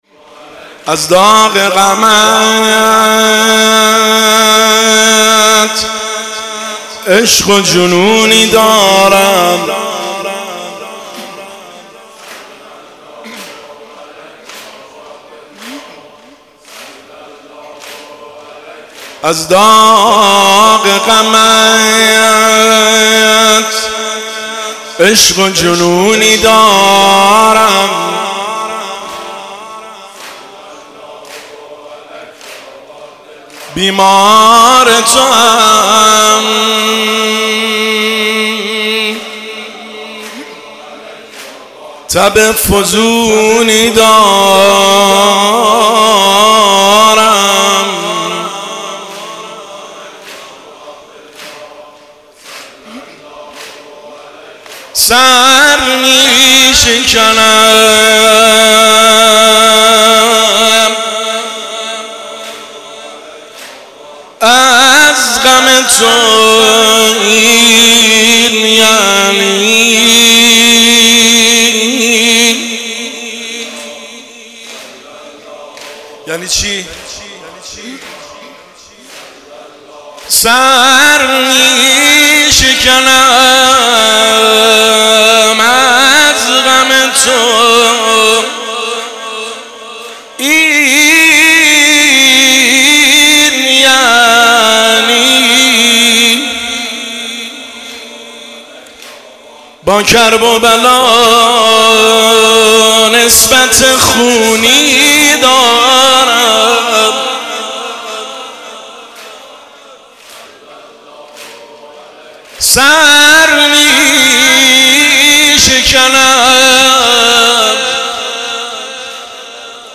هیئت هفتگی 17 اردیبهشت 1404
شعرخوانی